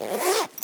action_open_backpack_4.ogg